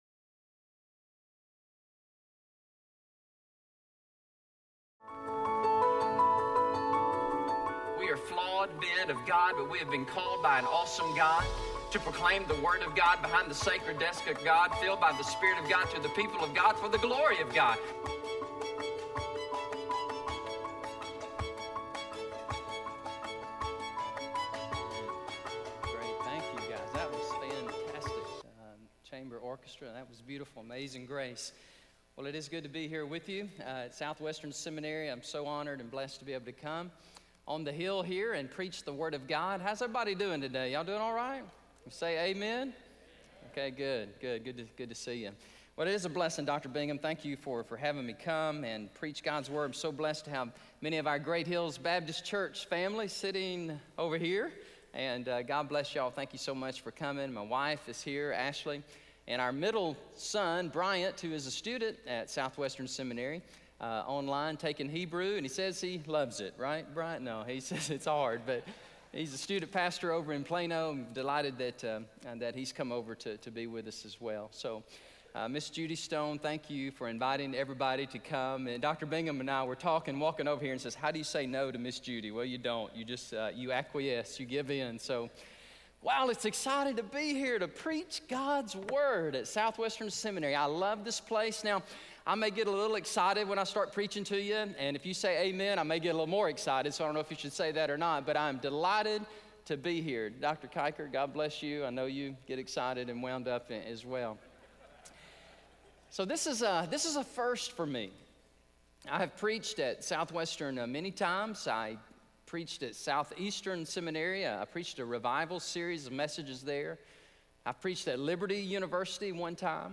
in SWBTS Chapel on Thursday November 1, 2018
SWBTS Chapel Sermons